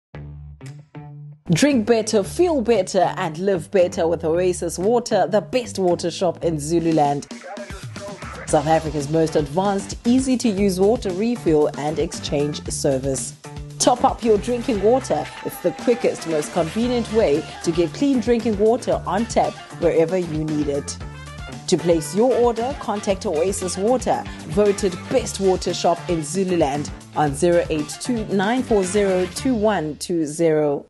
authentic, authoritative, soothing
Oasis Water advert demo